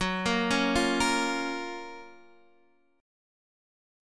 GbM7 Chord